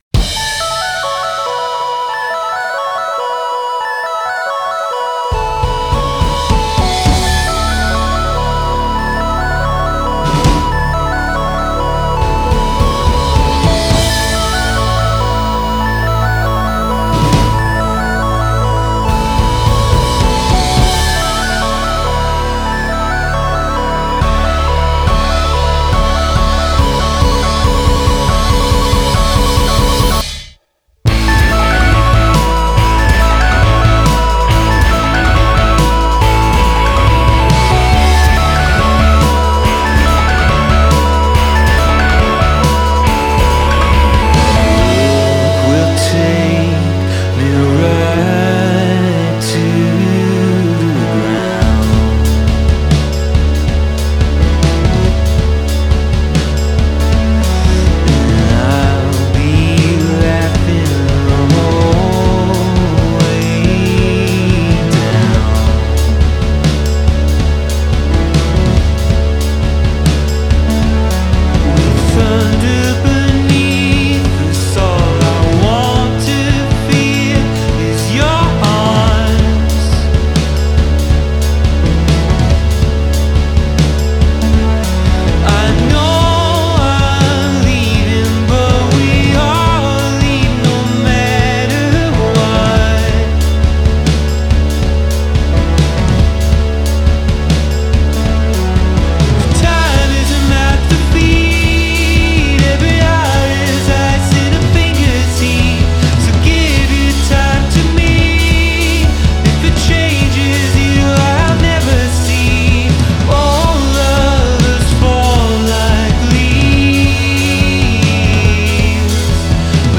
I am loving the layers of sound
The SF trio release their new album